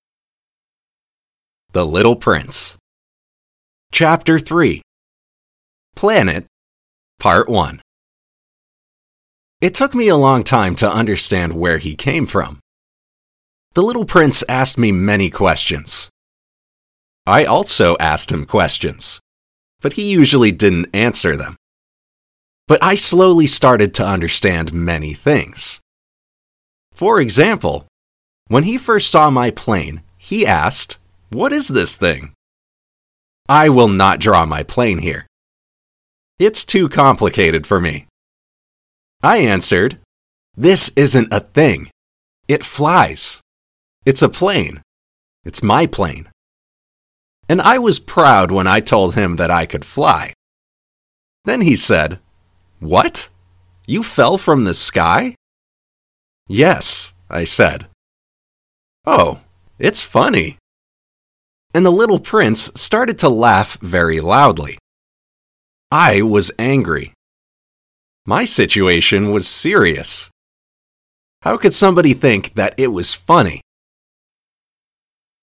native speakers